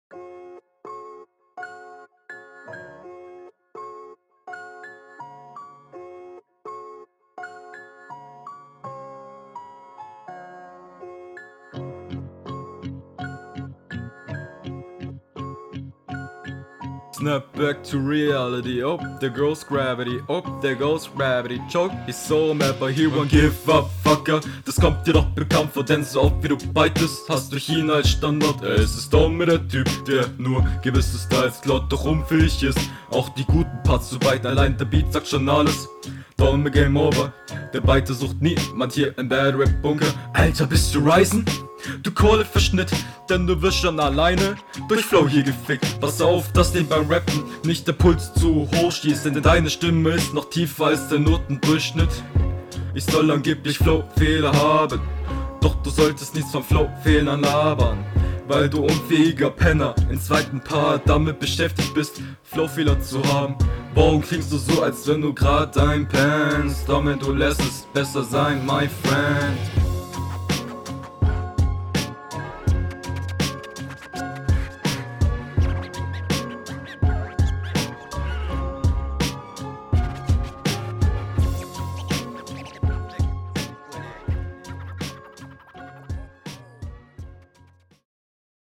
Flow: Jo das ist alles mehr aufn Takt und straighter gerappt aber n paar patterns …
Flow: Flowlich bist du nicht so stark wie dein Gegenr, Punkt an hr Text: gute …
Man merkt direkt, dass deine Stimme dünner als bei deinem Gegner ist, flowlich aber etwas …